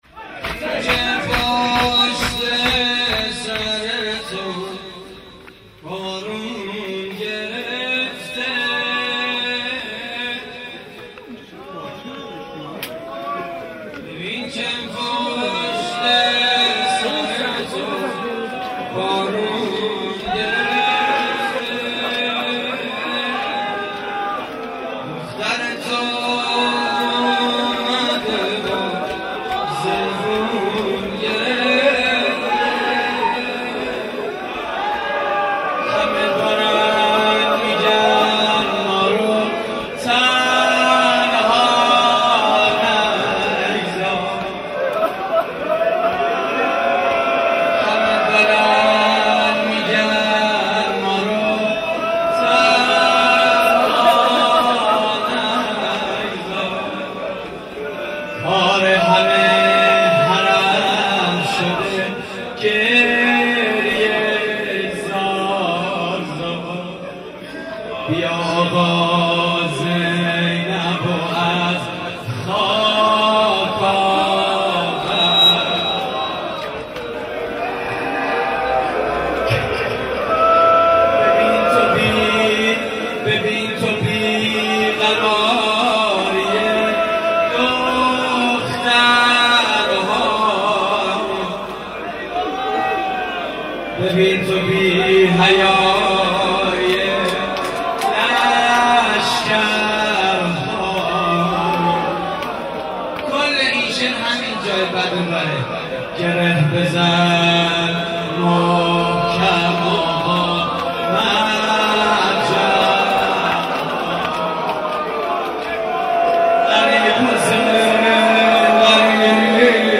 واحد: ببین که پشت سر تو بارون گرفته
مراسم عزاداری شب عاشورای حسینی (محرم 1432)